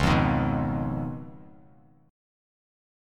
Listen to Db9 strummed